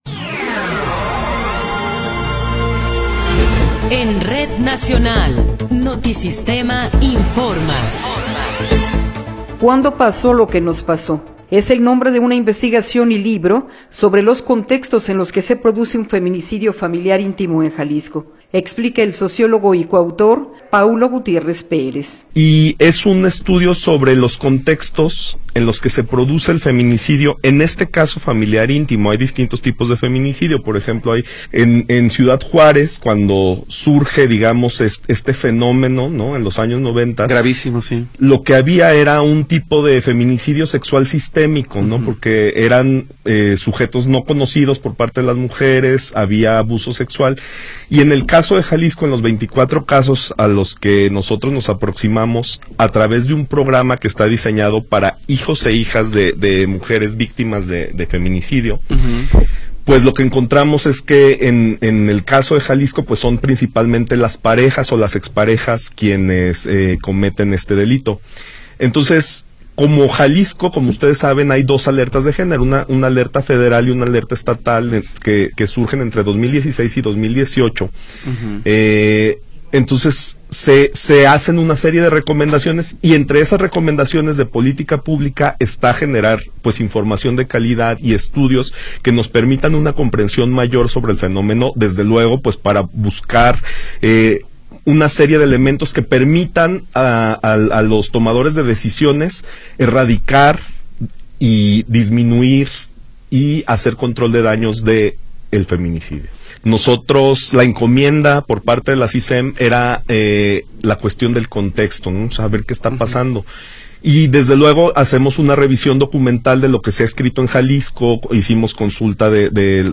Noticiero 21 hrs. – 31 de Marzo de 2024
Resumen informativo Notisistema, la mejor y más completa información cada hora en la hora.